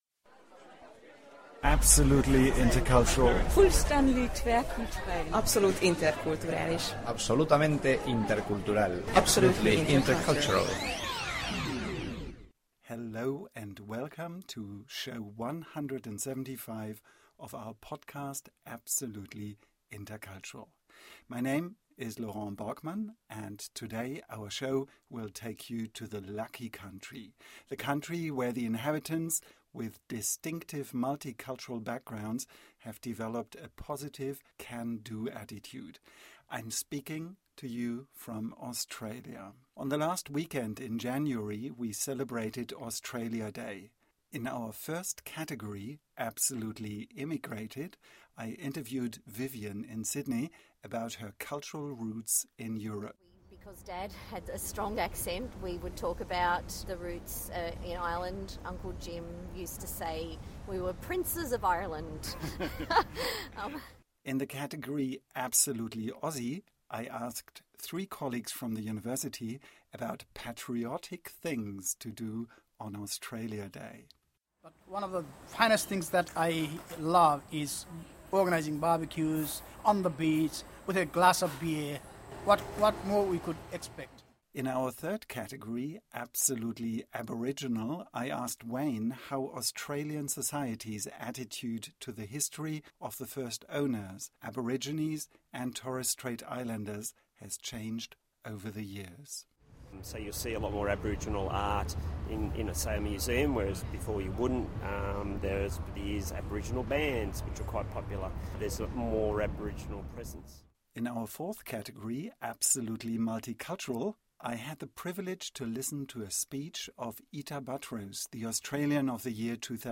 Listen to my interviewees in Australia. On the last weekend in January we celebrated Australia Day, and I took along the microphone to share my impressions with you.